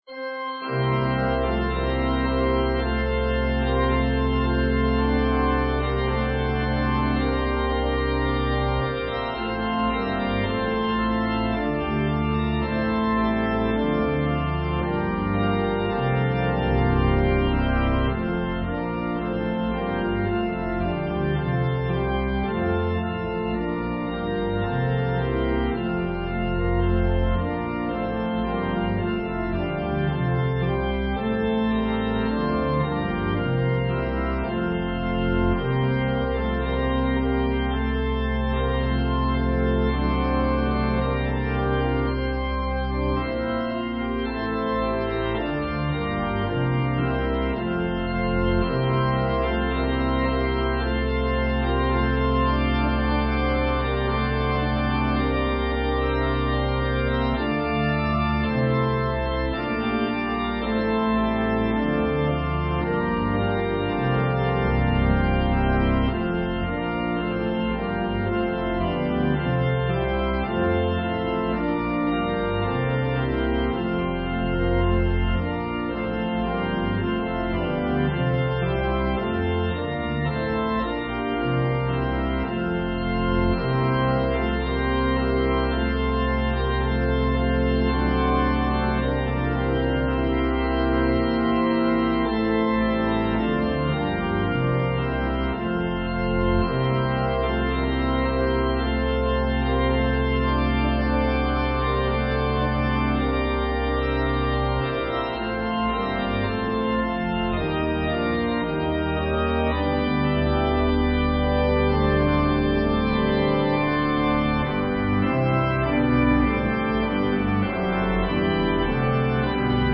An organ solo version of Mack Wilberg's first arrangement of this hymn from 1999.
Voicing/Instrumentation: Organ/Organ Accompaniment We also have other 14 arrangements of " Guide Us, Oh Thou Great Jehovah ".